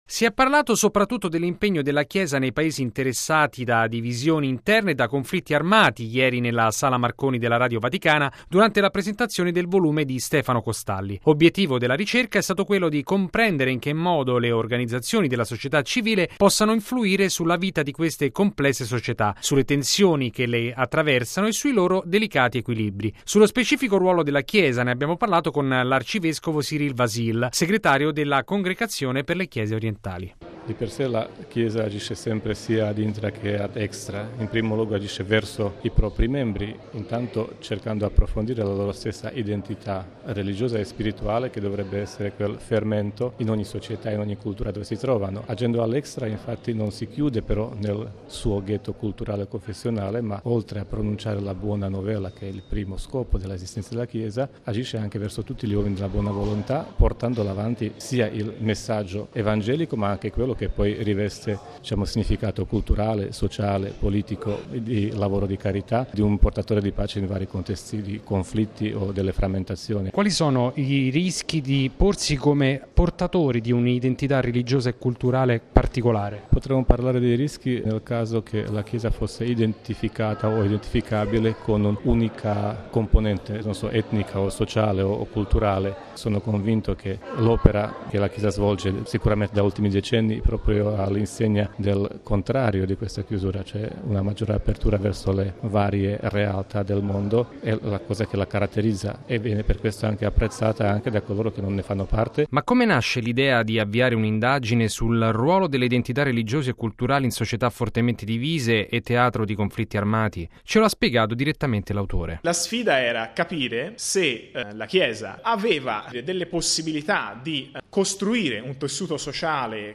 Sullo specifico ruolo della Chiesa, abbiamo interpellato l’arcivescovo Cyril Vasil, segretario della Congregazione per le Chiese Orientali.